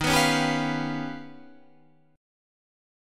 D#+9 Chord
Listen to D#+9 strummed